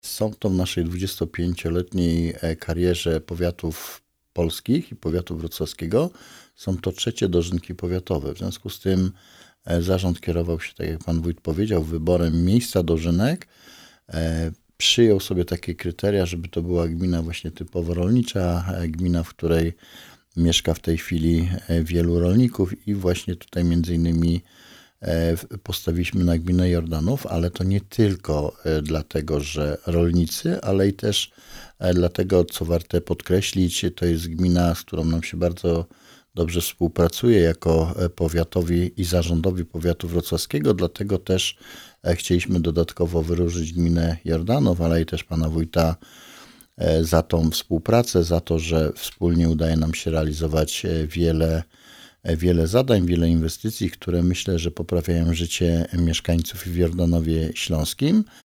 – Dożynki odbędą się w gminie Jordanów Śląski – podkreśla Roman Potocki, starosta Powiatu Wrocławskiego.